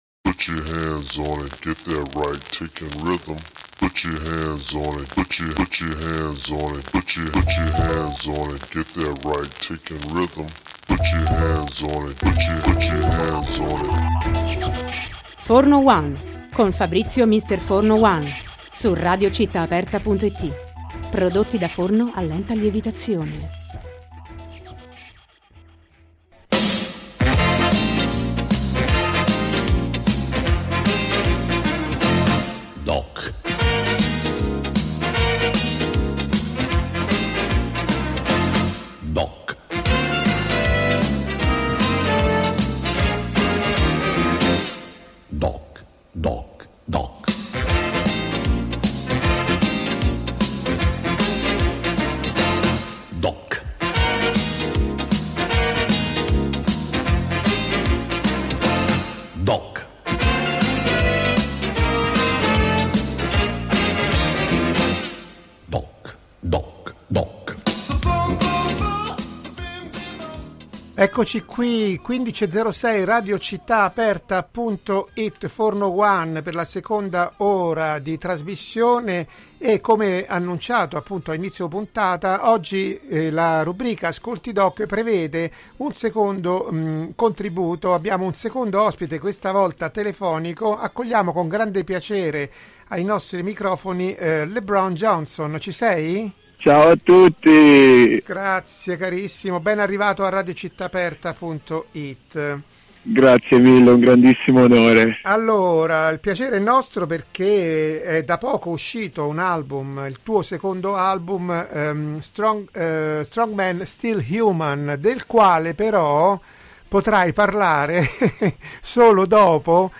Abbiamo raggiunto telefonicamente